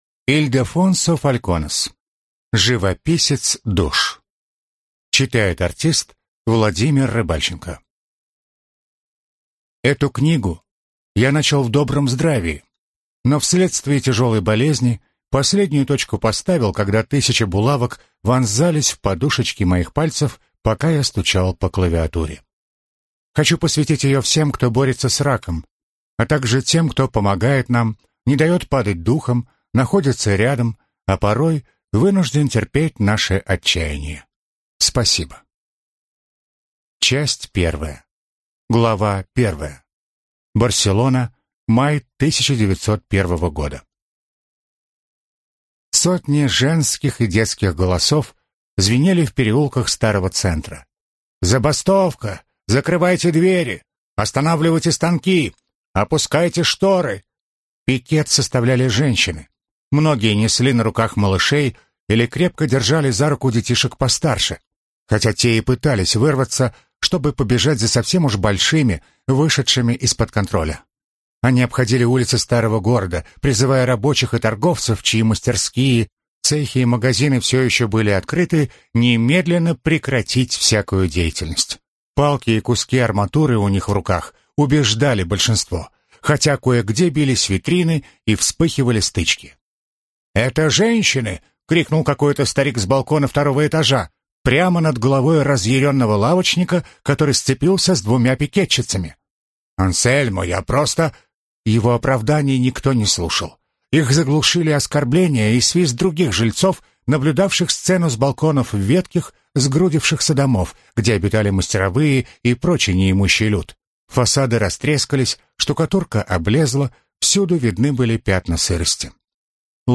Аудиокнига Живописец душ | Библиотека аудиокниг